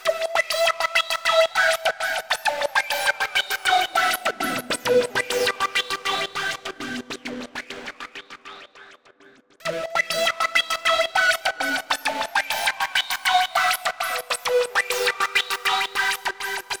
Em (E Minor - 9A) Free sound effects and audio clips
• Distorted Texture Laser Seq.wav
Distorted_Texture_Laser_Seq__IiV.wav